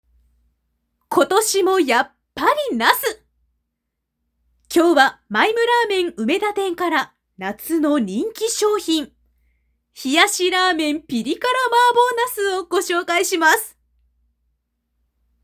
年齢 2002年⽣まれ（23歳） ⾝⻑ 154cm 出⾝地・⽅⾔ 香川県・讃岐弁 趣味 旅行、舞台鑑賞、パン・ケーキ作り、筋トレ 特技 歌、テニス、ピアノ、空手、朗読、変顔 資格・免許 普通自動車免許（AT限定） ボイスサンプル 天気予報 インフォマ CM